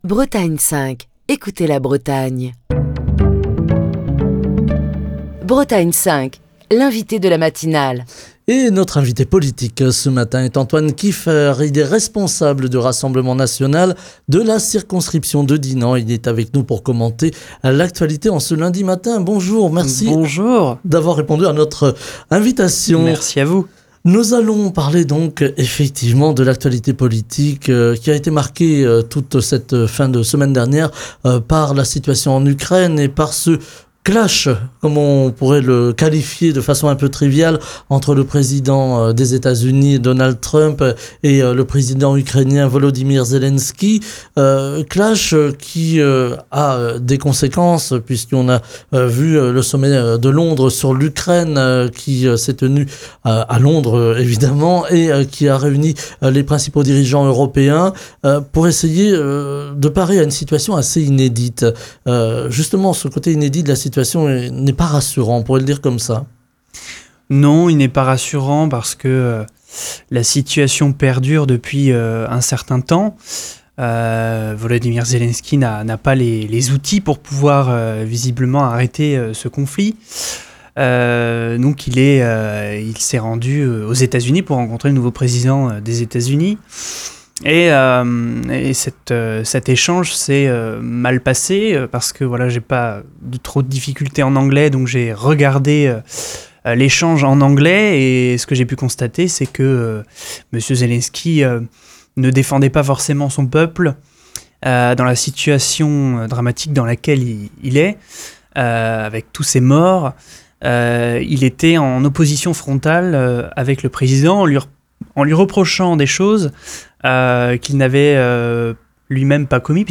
était l'invité politique de la matinale de Bretagne 5 ce lundi. Il est revenu sur l'altercation qui a eu lieu vendredi dans le Bureau ovale entre le président américain Donald Trump et le président ukrainien Volodymyr Zelensky.